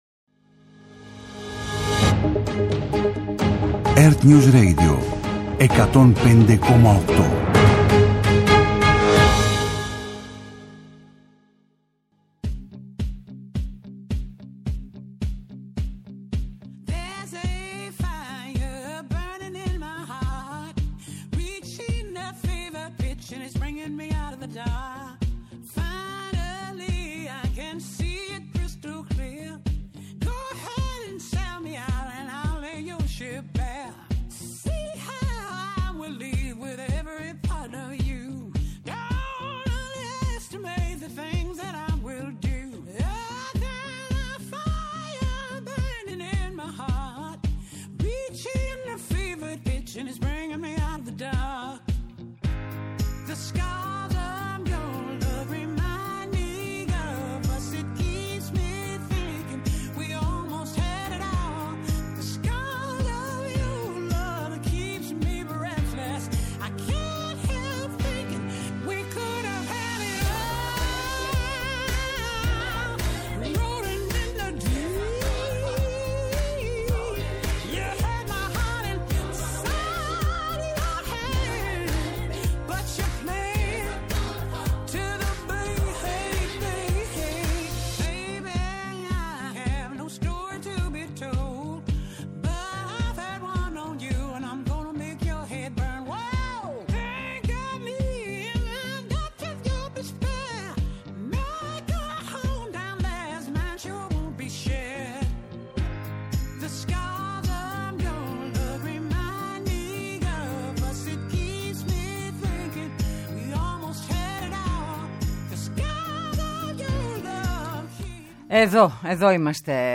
-Ο Κωνσταντίνος Τασούλας, Πρόεδρος της Δημοκρατίας, από το Φόρουμ των Δελφών